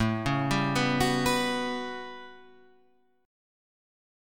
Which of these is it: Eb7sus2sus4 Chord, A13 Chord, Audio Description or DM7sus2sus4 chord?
A13 Chord